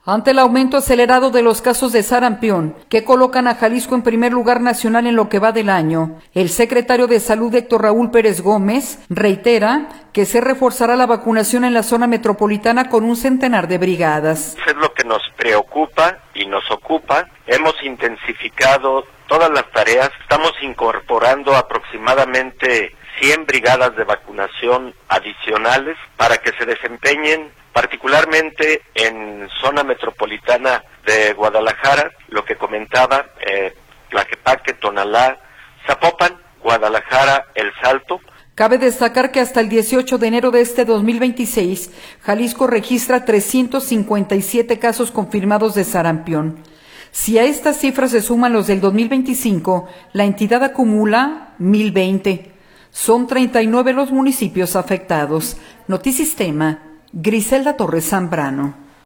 Ante el aumento acelerado en los casos de sarampión, que colocan a Jalisco en primer lugar nacional en lo que va del año, el Secretario de Salud, Héctor Raúl Pérez Gómez, reitera en que se reforzará la vacunación en la zona metropolitana con un centenar de brigadas.